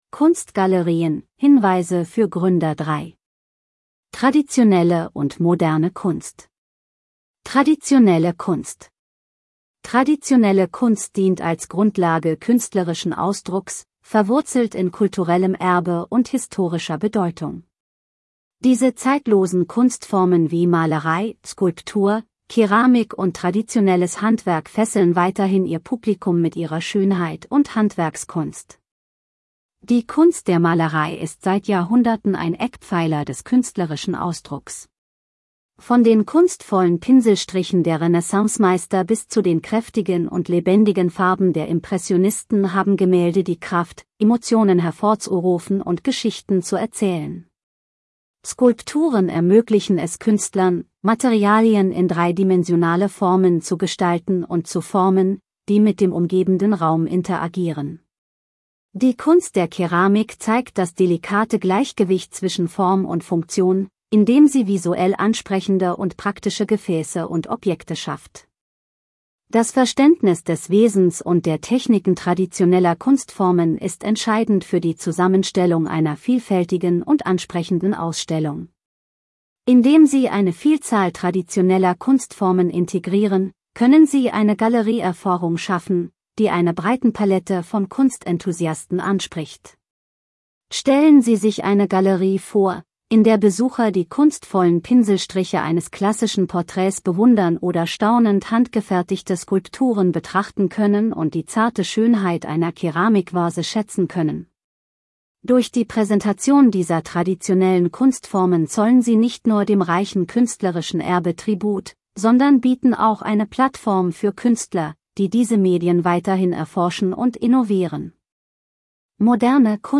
Der obige Text gelesen von unserer KI-Stimme Louisa